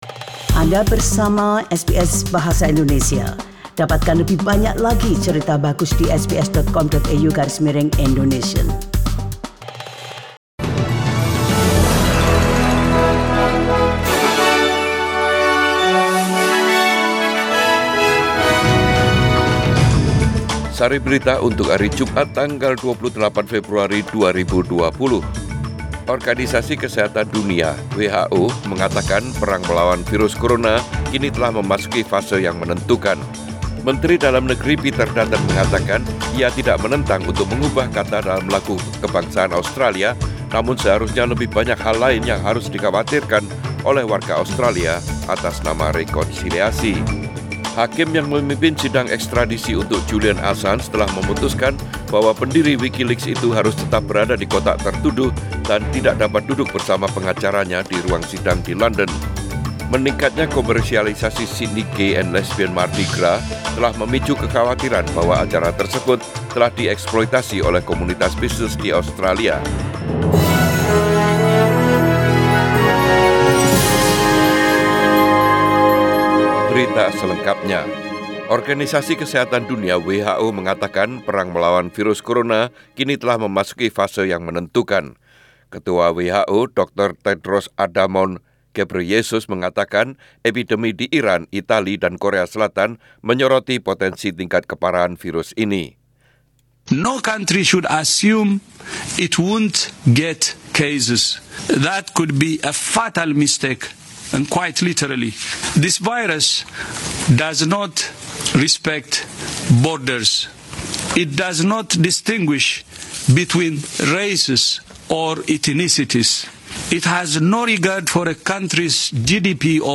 SBS Radio News in Indonesian - 28 Feb 2020